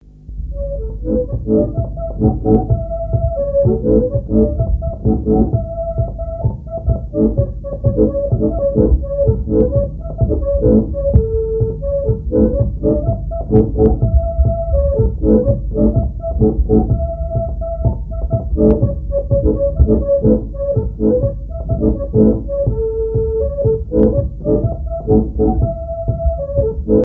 danse-jeu : youchka
Répertoire à l'accordéon diatonique
Pièce musicale inédite